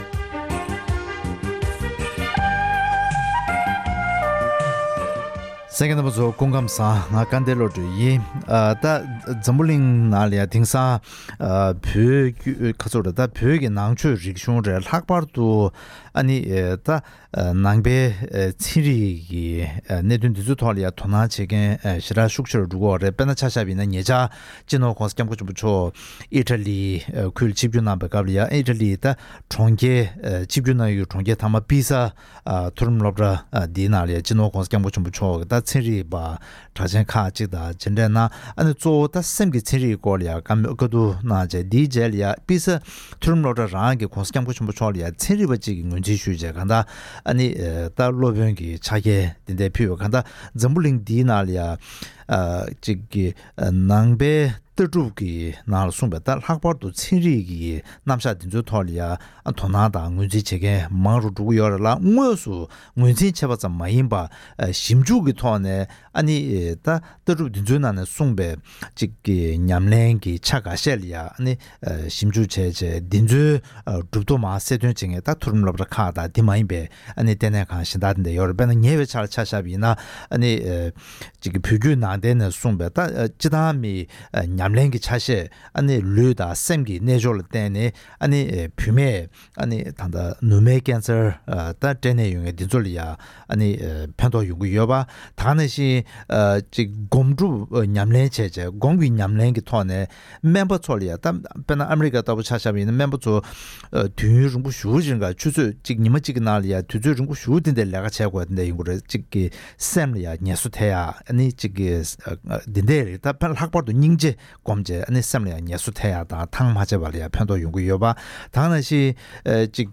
༄༅། །ཐེངས་འདིའི་གནད་དོན་གླེང་མོལ་གྱི་ལེ་ཚན་ནང་དུ།